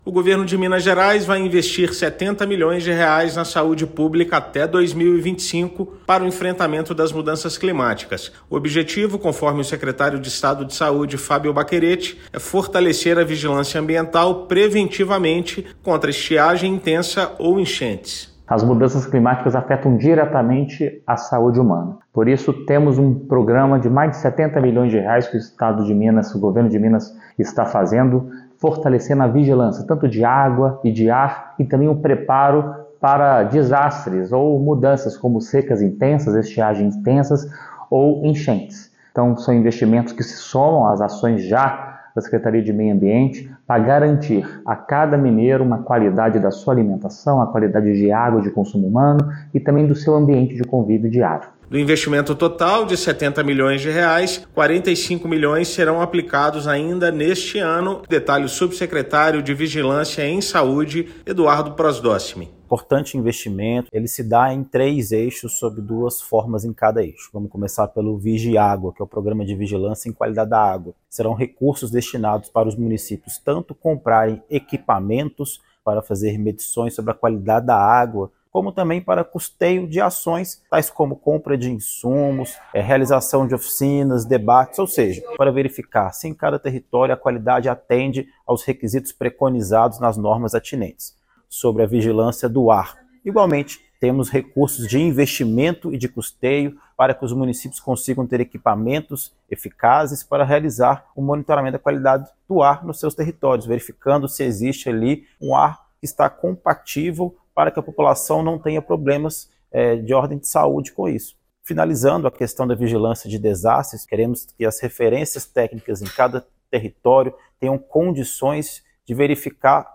[RÁDIO] Minas investe R$ 70 milhões na saúde pública para enfrentamento dos efeitos das mudanças climáticas
Recursos vão custear ações para garantir a qualidade da água, do ar e a prevenção de agravos no contexto de desastres. Ouça matéria de rádio.